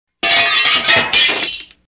碎裂声.mp3